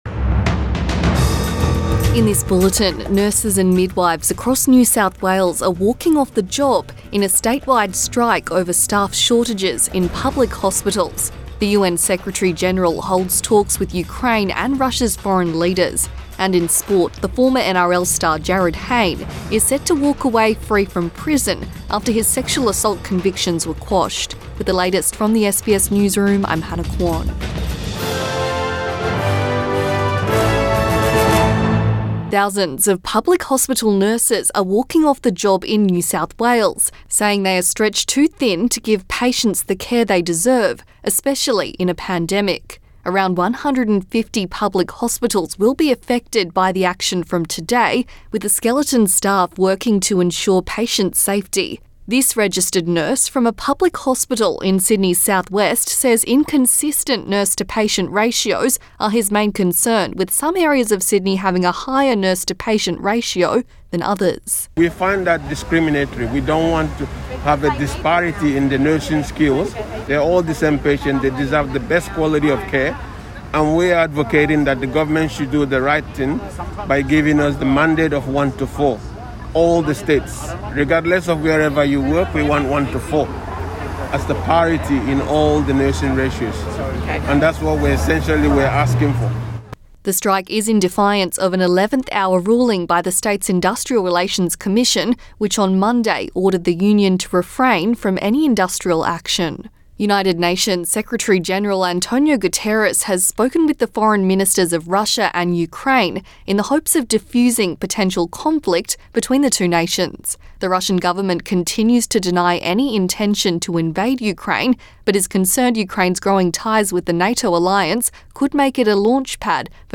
Midday bulletin 15 February 2022